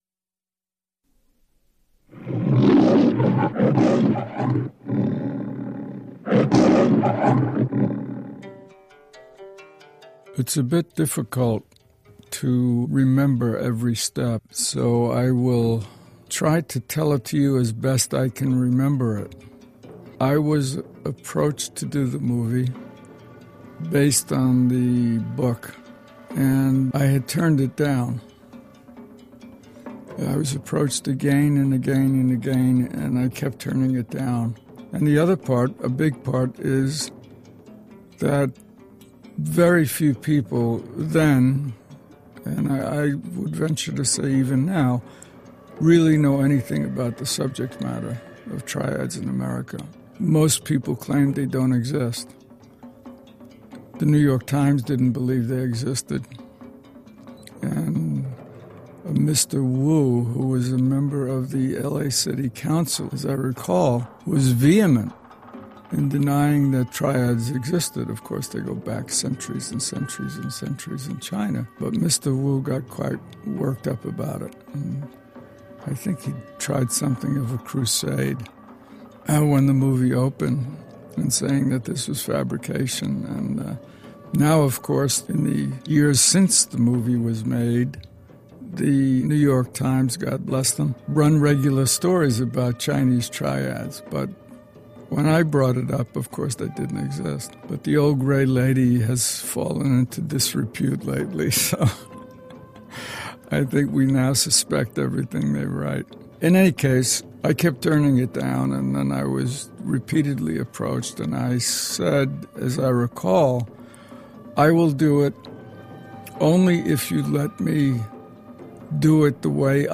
Year-of-the-Dragon-1985-Audio-Commentary-1.mp3